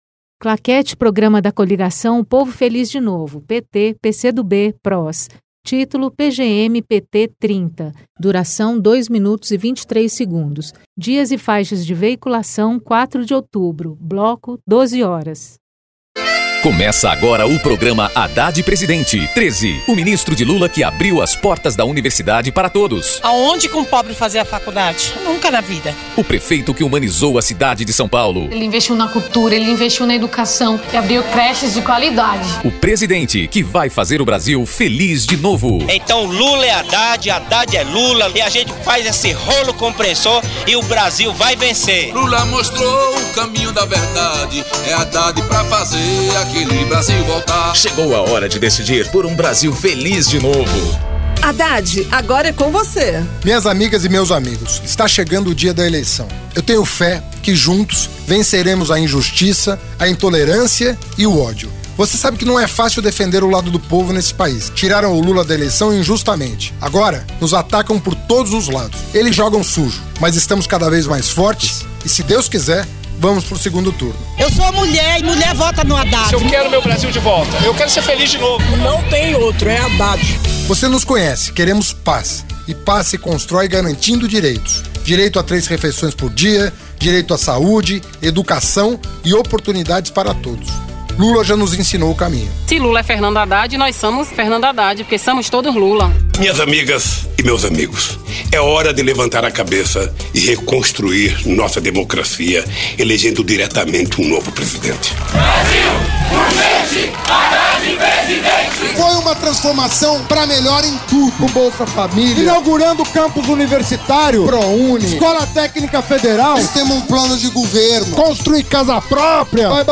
TítuloPrograma de rádio da campanha de 2018 (edição 30)
Gênero documentaldocumento sonoro